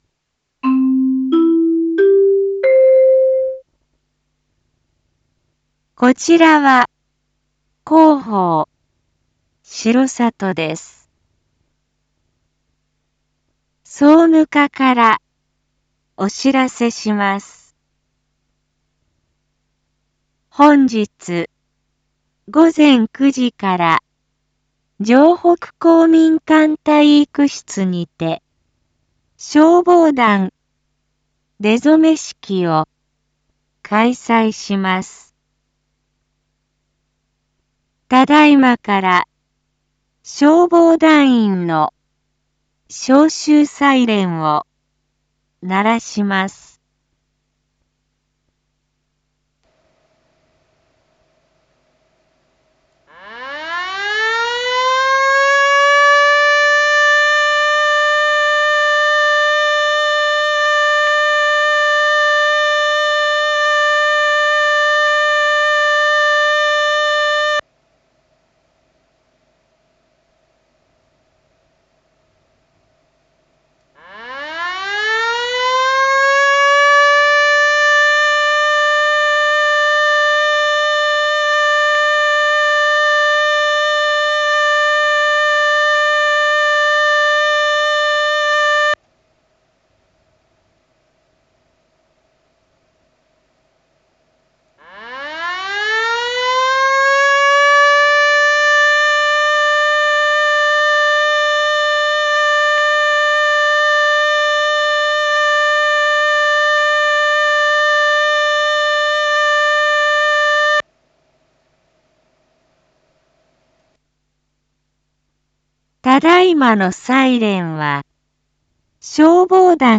Back Home 一般放送情報 音声放送 再生 一般放送情報 登録日時：2023-01-08 07:01:02 タイトル：消防団出初式招集サイレン インフォメーション：こちらは、広報しろさとです。